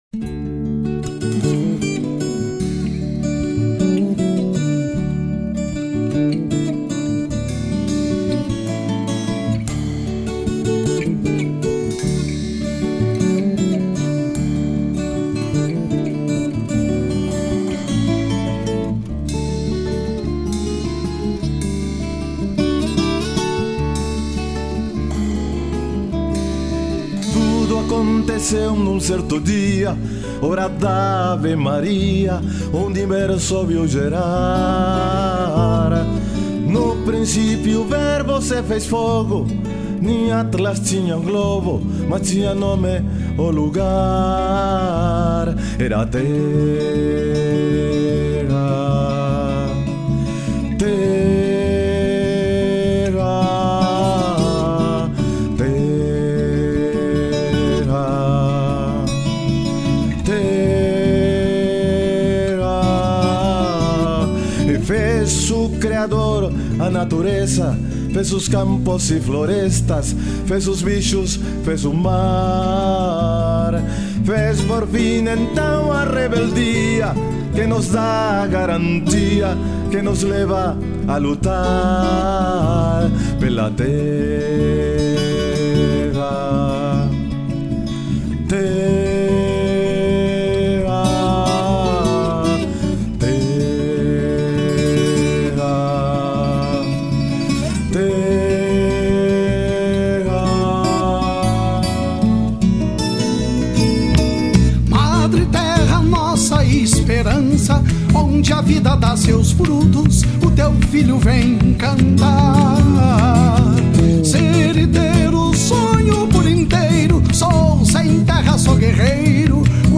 03:45:00   Ciranda